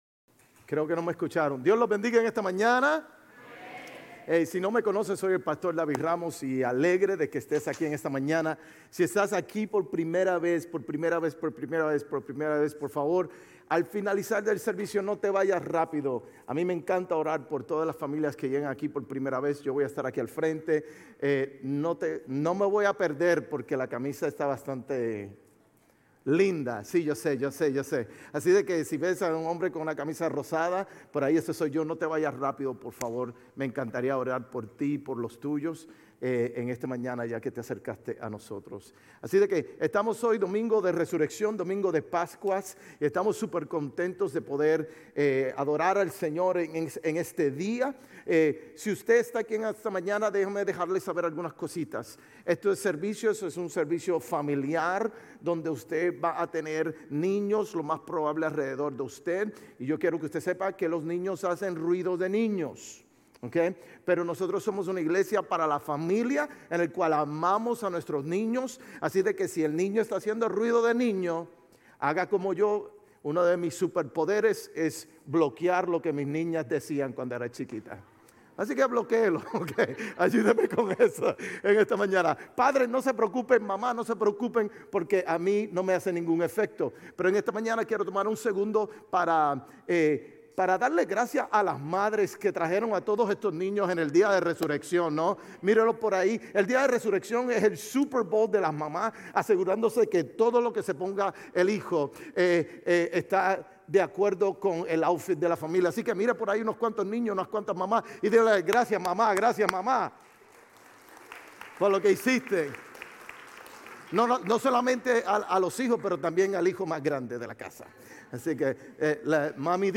Sermones Grace Español 4_20 Grace Espanol Campus Apr 20 2025 | 00:39:06 1x 00:00 / 00:39:06 Subscribe Share RSS Feed Share Link Embed